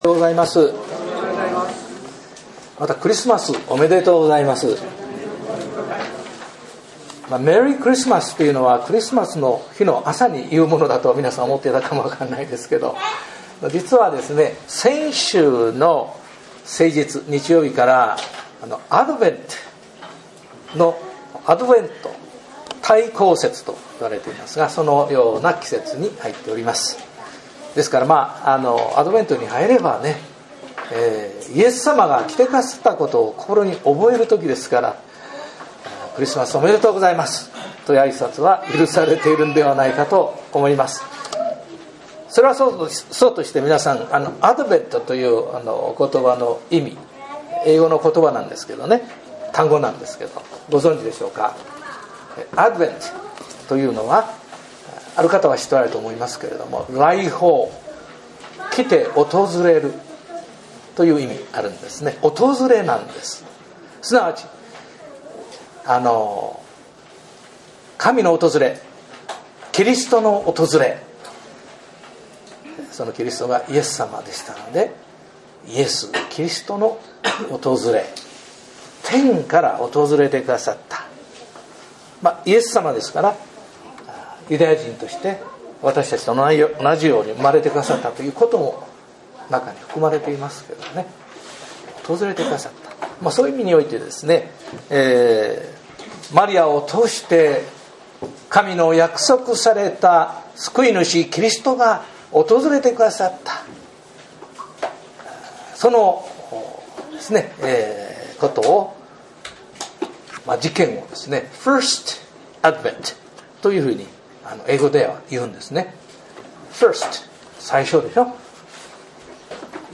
牧師